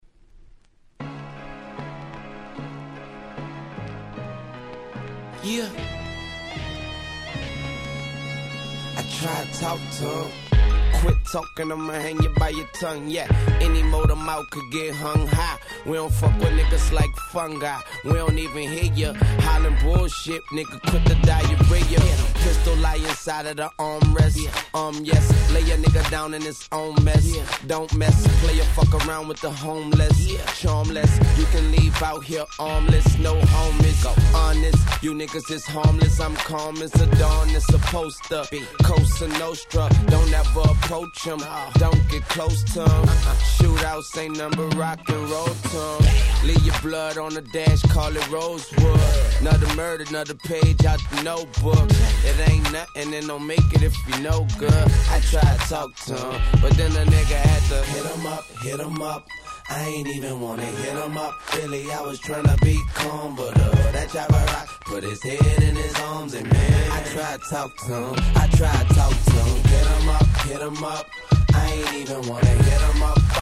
05' Super Hit Hip Hop LP !!
Dirty South寄りの曲が目立った前作から打って変わって本作はSoulfulでMellowな格好良い曲が満載。